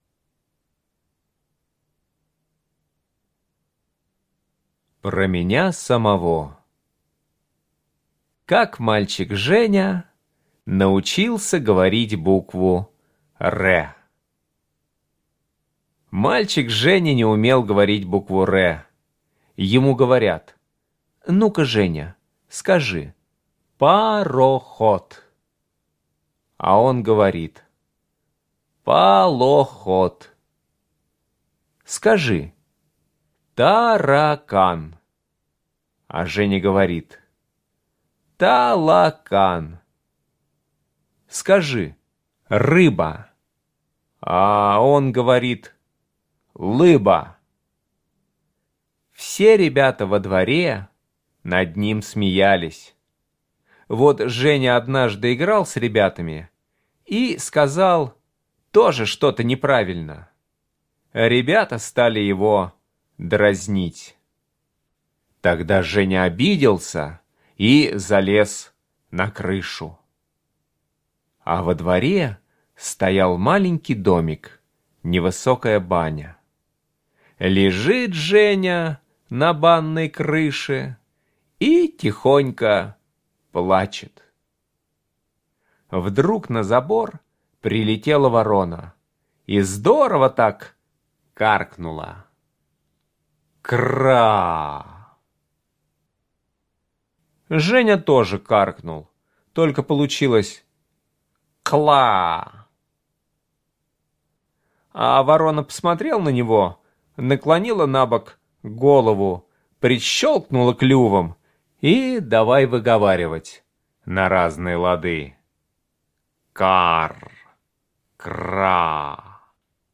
Слушайте Как мальчик Женя научился говорить букву Р - аудио рассказ Чарушина Е.И. Как ворона научила мальчика Женю говорить букву Р.